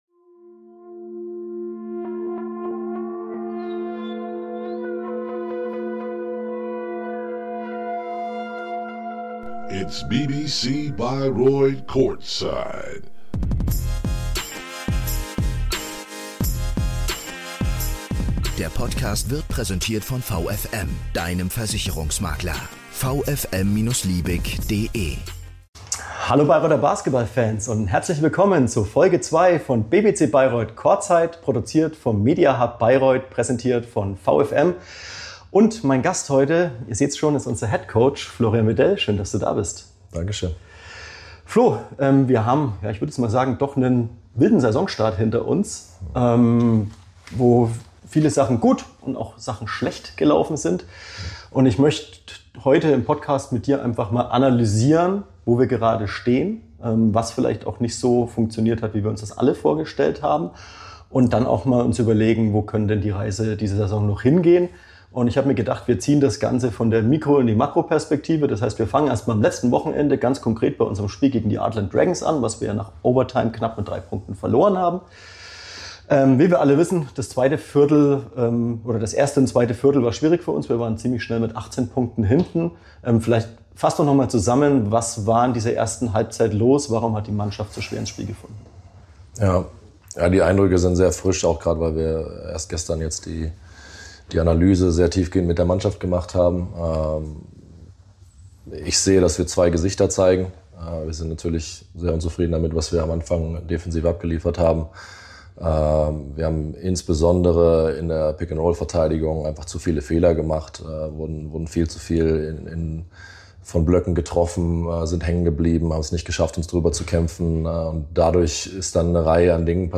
Ein Gespräch über Verantwortung, Leidenschaft und den Glauben daran, dass aus Rückschlägen Energie wachsen kann.